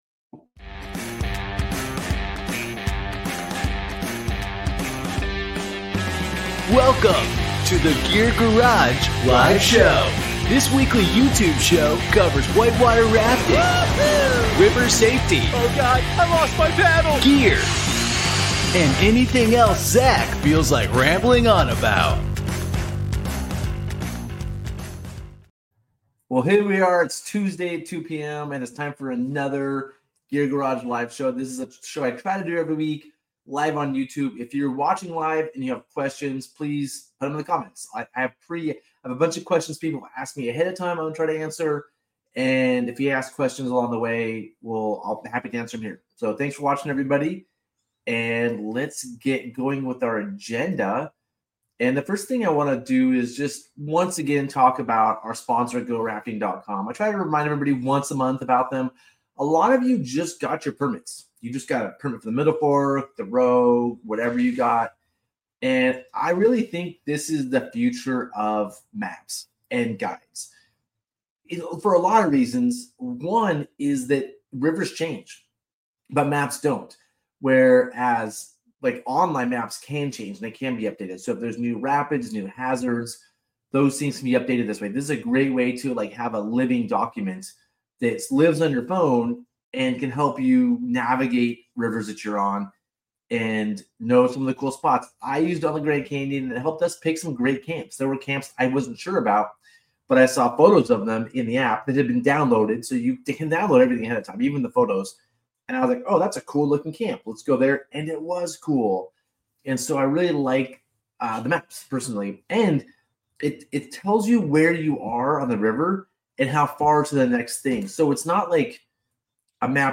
This podcast is the audio version of the Gear Garage Live Show, where we answer submitted questions and talk all things whitewater.